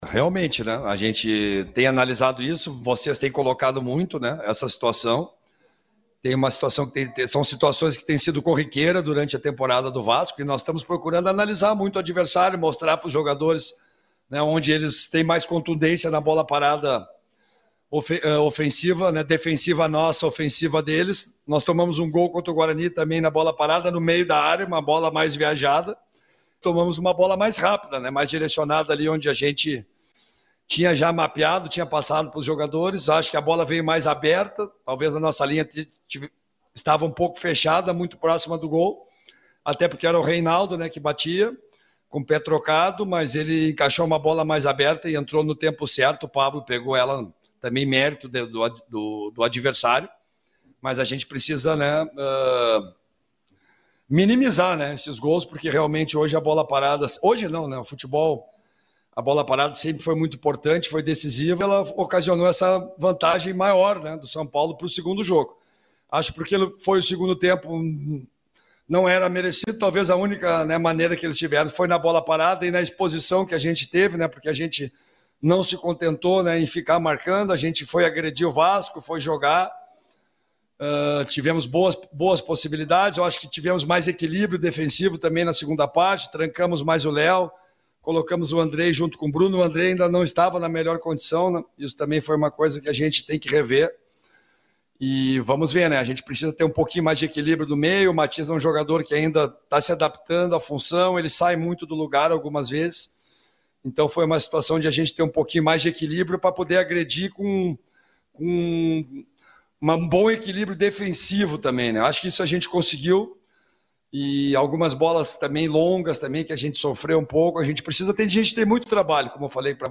Na entrevista coletiva o técnico Lisca reconheceu é problema e revelou que vem conversando com os jogadores de defesa e meio campo sobre o posicionamento em campo e até porque não tem tempo de treinamentos.